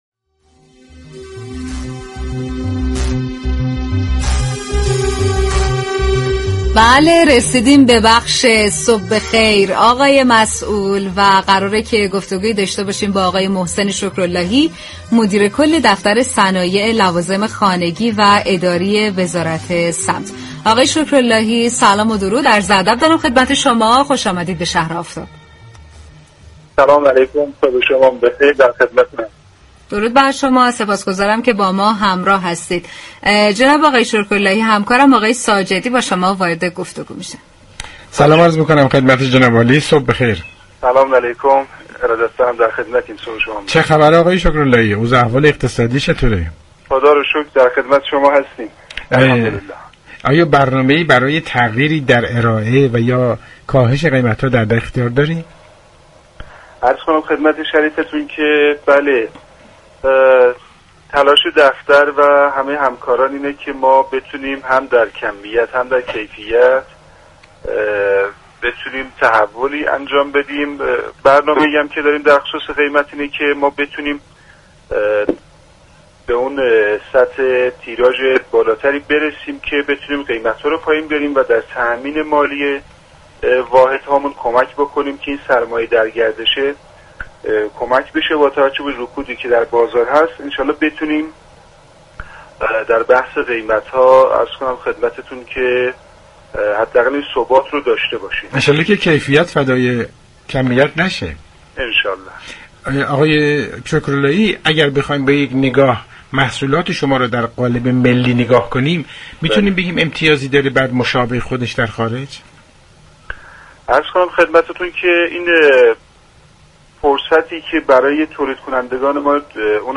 به گزارش پایگاه اطلاع رسانی رادیو تهران،محسن شكرالهی مدیركل دفتر صنایع لوازم خانگی واداری وزارت صمت در‌ گفت‌وگو با شهر آفتاب رادیو تهران گفت: تلاش می‌كنیم در كمیت و كیفیت تولید لوازم خانگی تحول ایجاد كنیم.